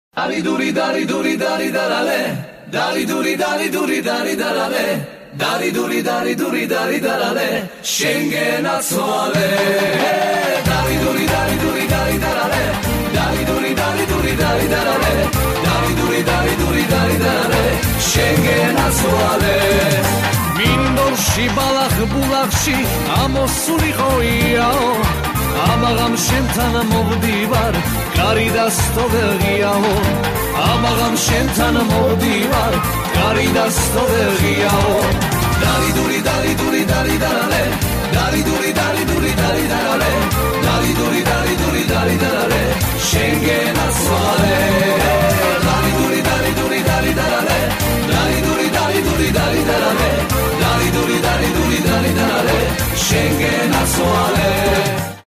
• Качество: 128, Stereo
мужской вокал
инструментальные
Народные
Грузинские
этнические